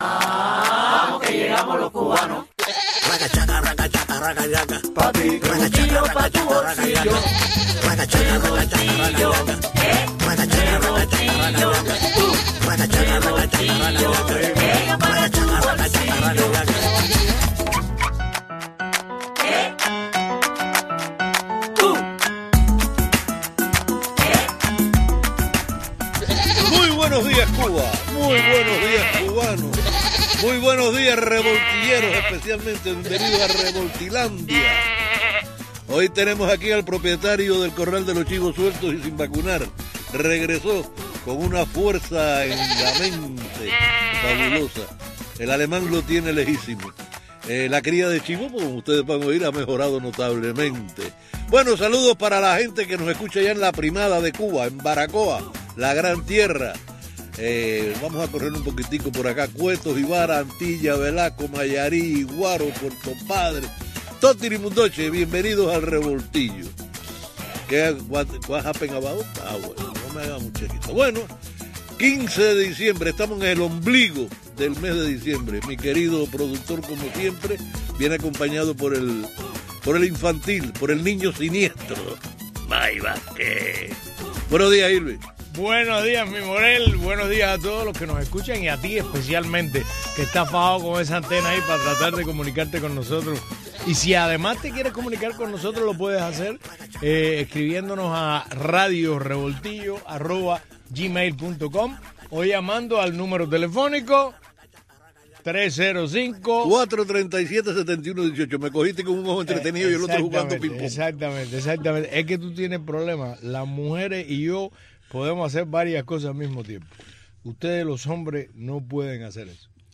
programa matutino